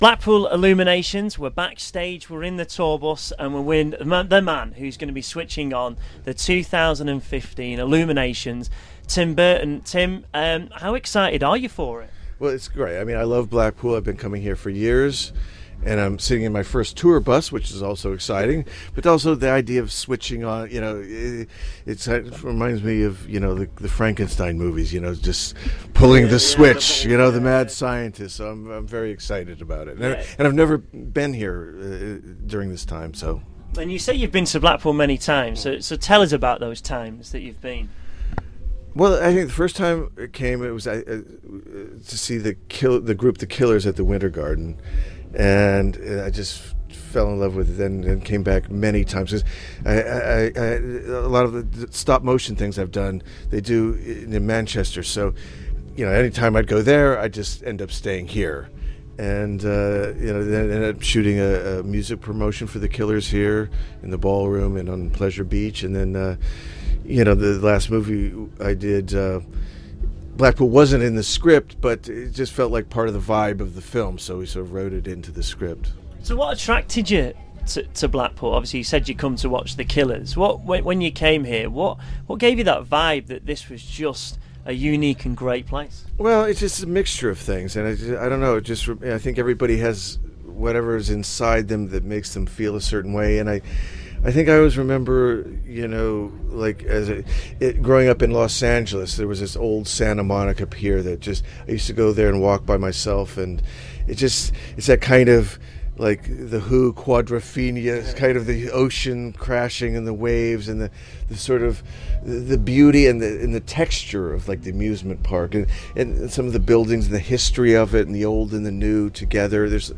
Listen when i caught up with Hollywood Director Tim Burton before he switched on the Blackpool Illuminations for 2015